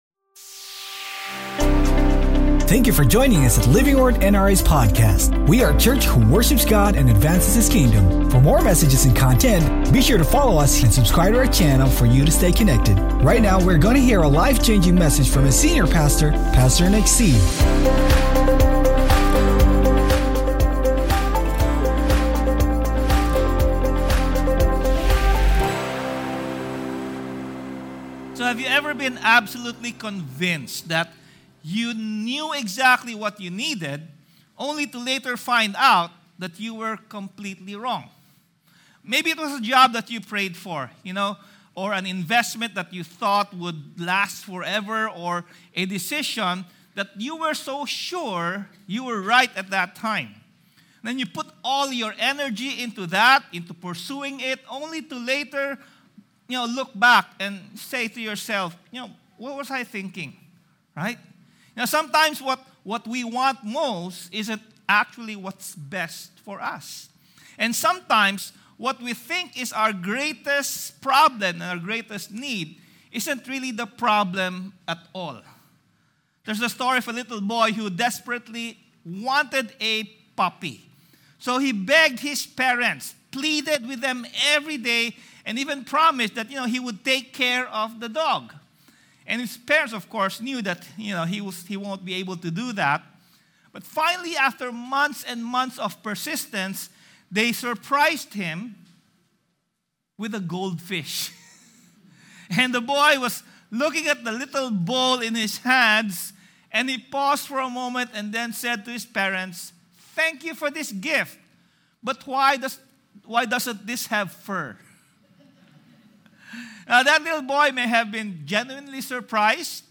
Faith is believing that God can, while trusting him even if he doesn’t. Sermon Title: FAITH THAT WALKS: WHEN JESUS MEETS OUR GREATEST NEED